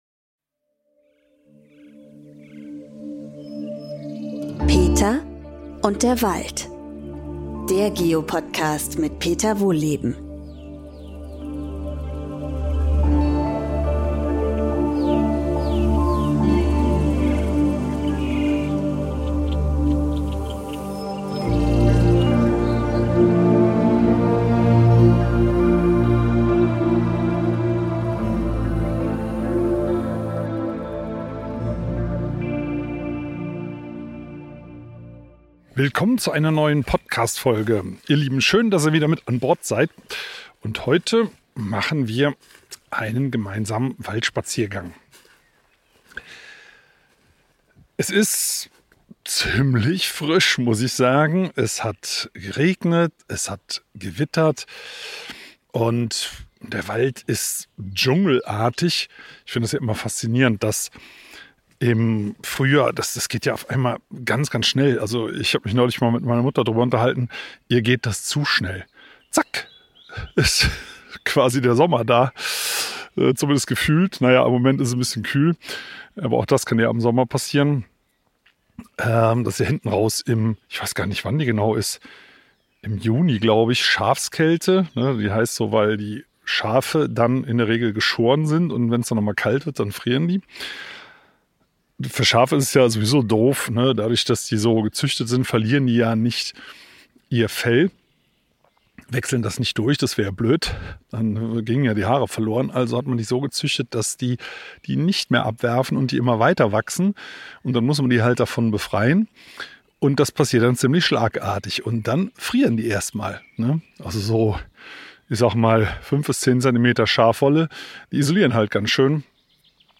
Da das Wetter kühl und feucht ist, geht es während dieses Waldspaziergangs vor allem um Schnecken.